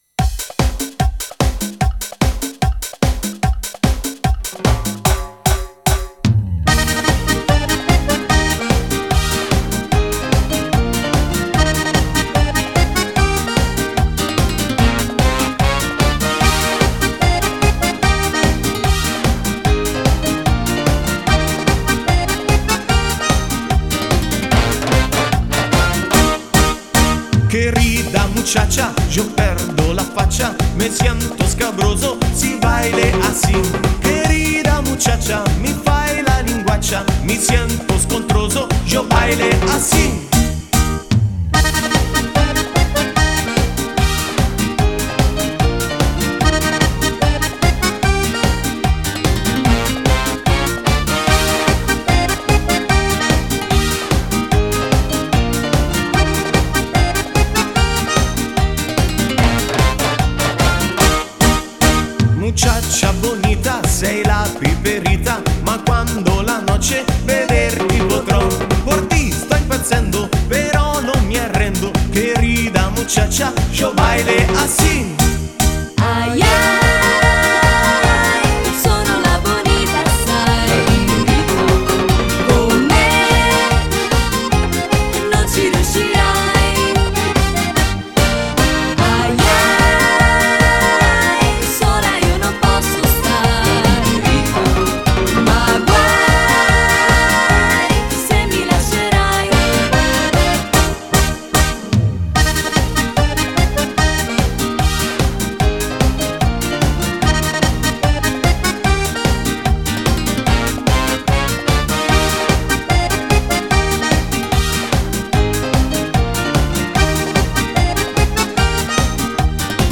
Nuovo Ballo di Gruppo 2011